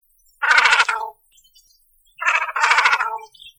cormoran.mp3